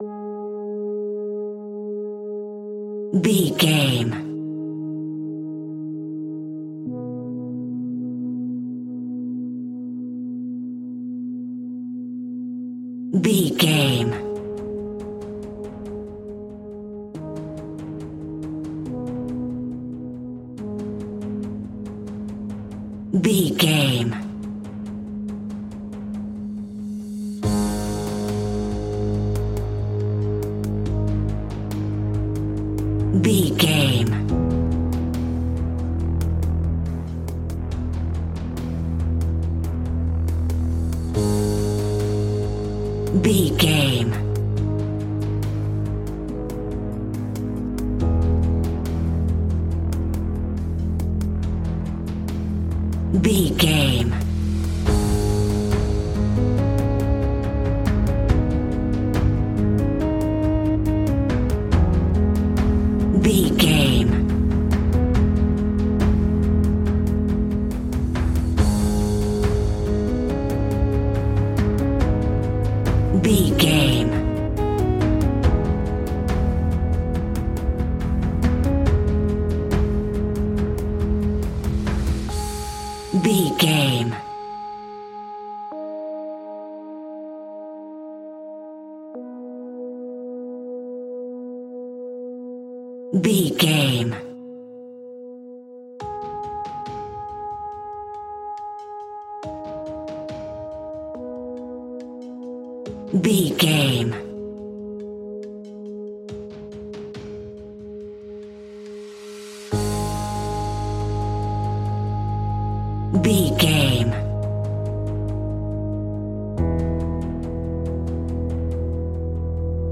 In-crescendo
Aeolian/Minor
G#
ominous
dark
haunting
eerie
synthesiser
drums
horror music